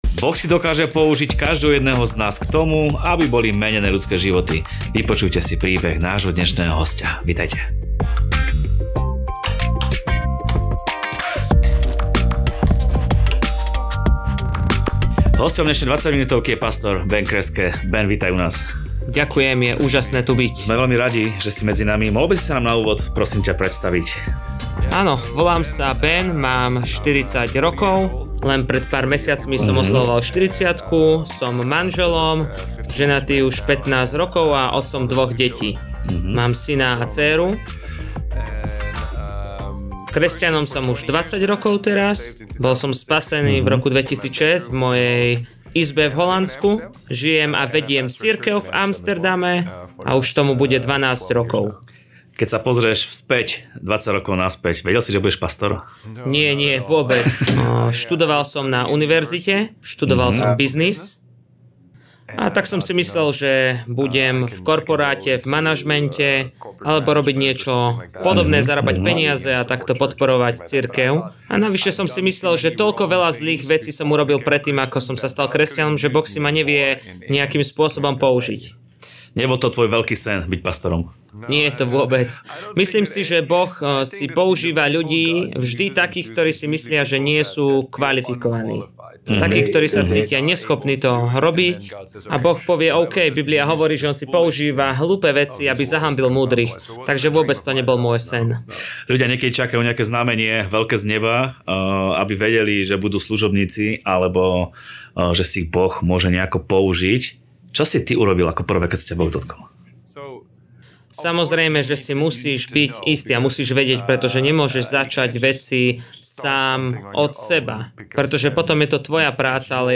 V rozhovore hovorí o tom, ako sa vyrovnával s pochybnosťami, strachom a pocitom nedostatočnosti.